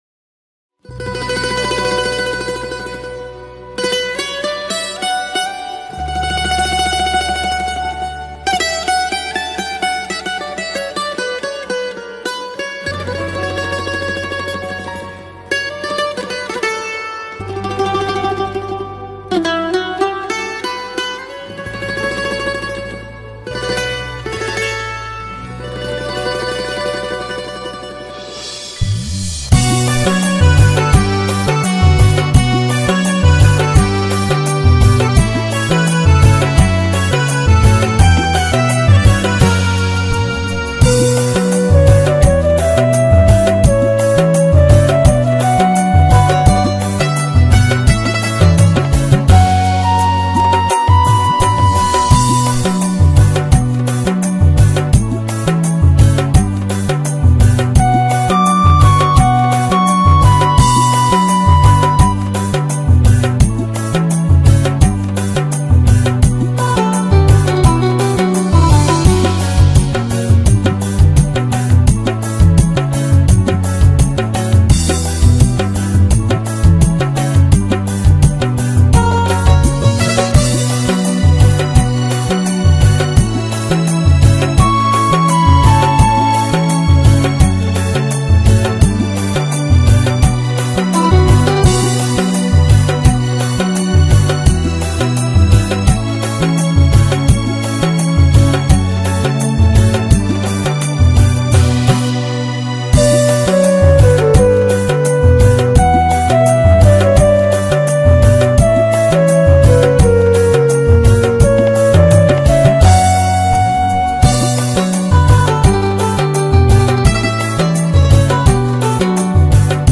Sinhala No Voice Karaoke Track Mp3 Download